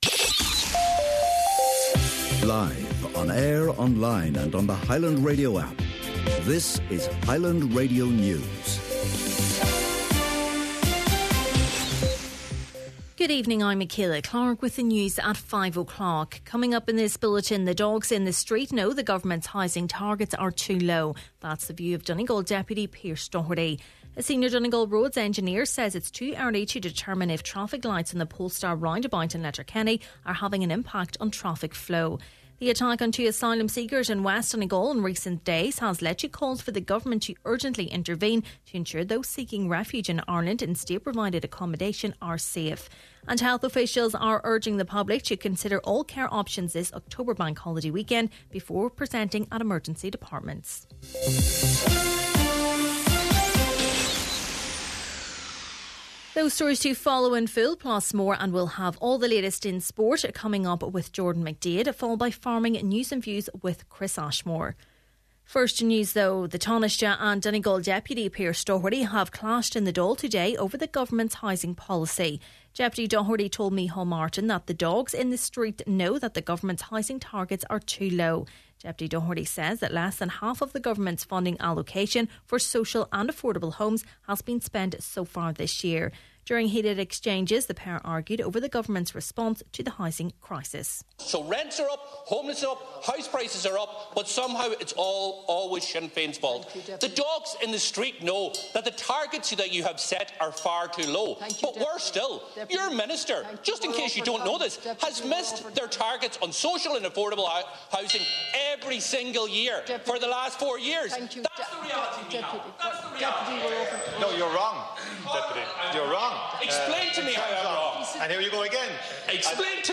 Main Evening News, Sport, Farming News and Obituaries – Thursday October 26th